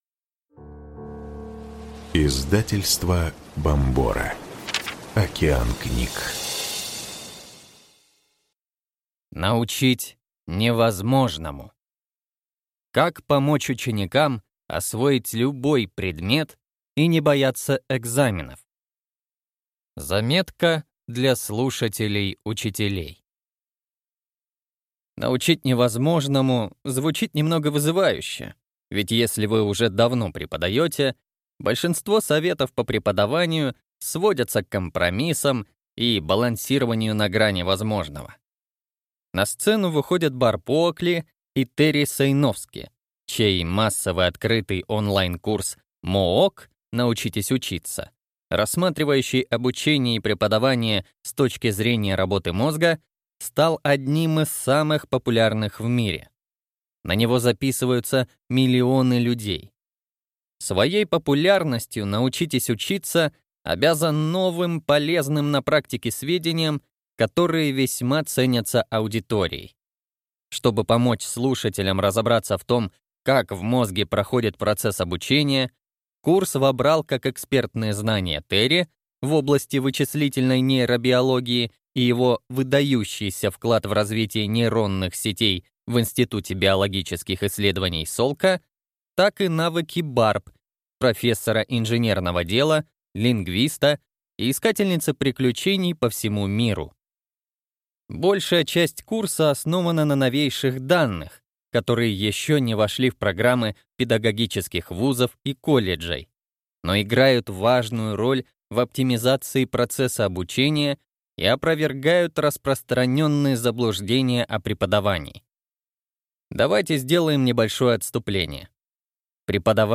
Аудиокнига Научить невозможному. Как помочь ученикам освоить любой предмет и не бояться экзаменов | Библиотека аудиокниг